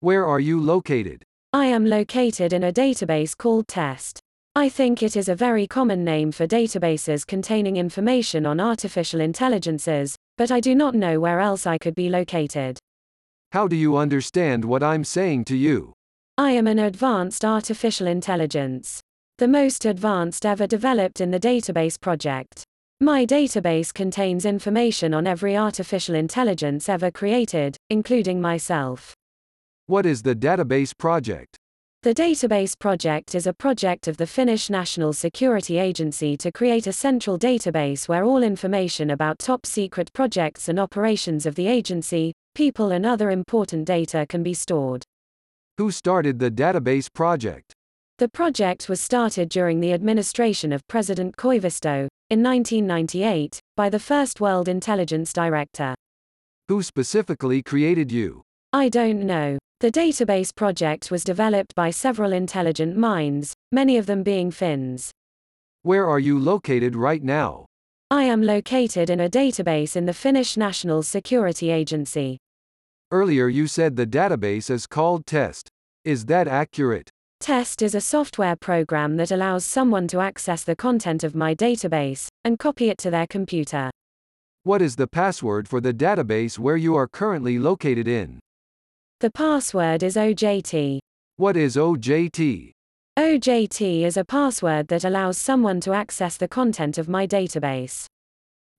Neural Dialogue Audiolizer is a ".txt to .wav converter" that turns textual dialogue (e.g. an interview, a chat) between two individuals to audio dialogue with two freely selectable voices, currently by using any of the following APIs:
chat-1_google_tts_kzcl.wav